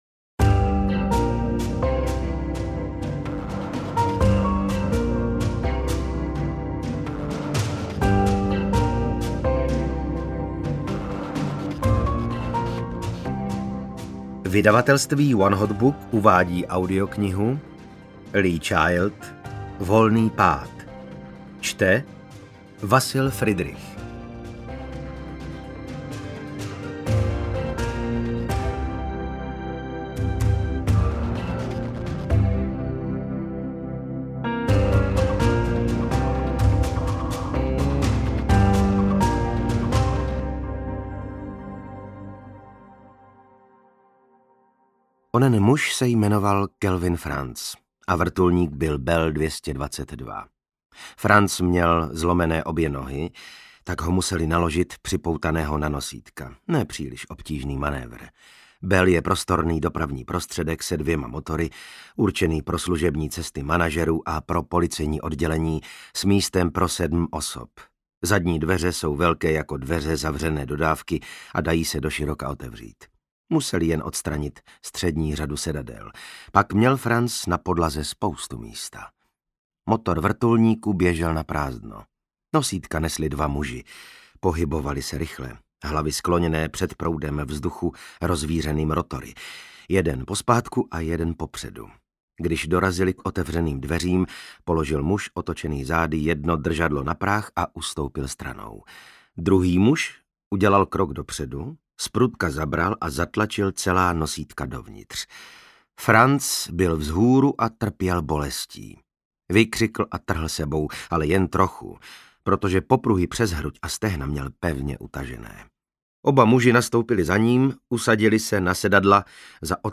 Audiokniha Jack Reacher: Volný pád, kterou napsal Lee Child, je 11. díl oblíbené série. Na kalifornskou poušť spadlo z nebe něco nečekaného – a nebyl to déšť ani meteorit, nýbrž chlápek s přelámanými hnáty, kterého kdosi vystrčil z helikoptéry.
Ukázka z knihy